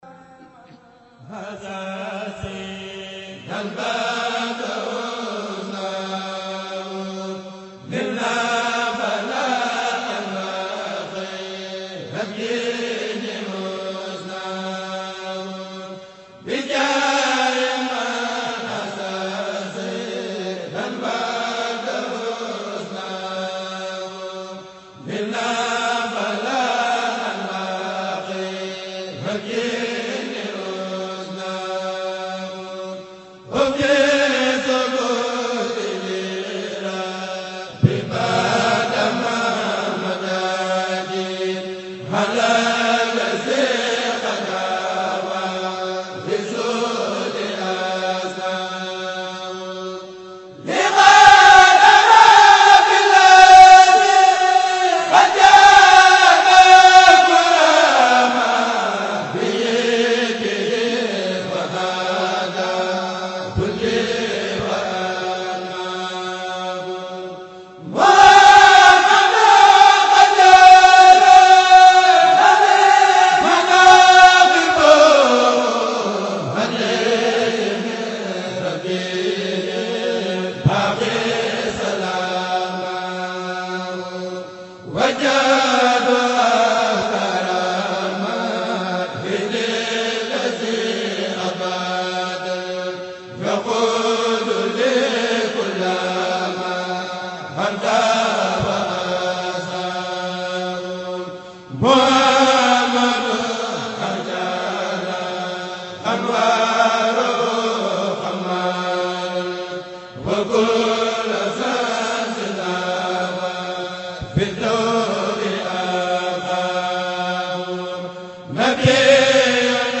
Ramadan 2018 : Écouter et Télécharger Les Prestations de Hizbut Tarqiyyah à la Résidence Cheikhoul Khadim de Touba (Mp3) Ramadan 2018 : Écouter (…)